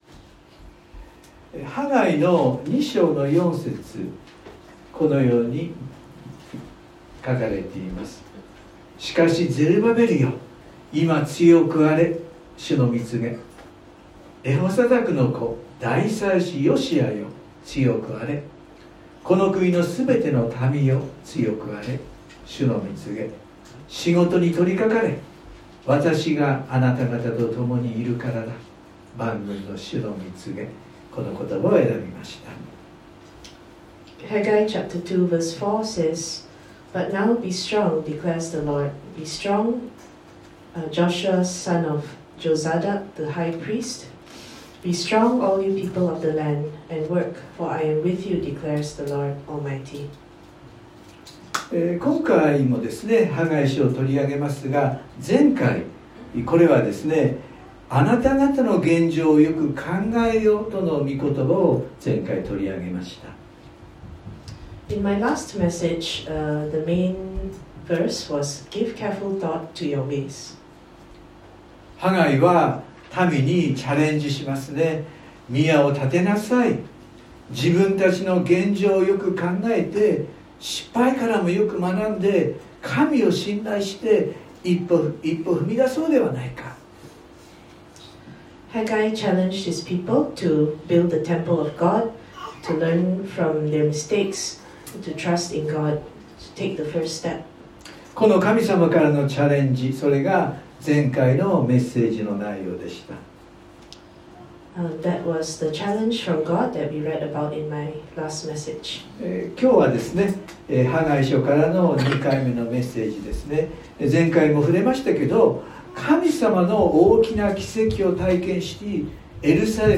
↓メッセージが聞けます。（日曜礼拝録音） 【iPhoneで聞けない方はiOSのアップデートをして下さい】 今日もハガイ書を取り上げます。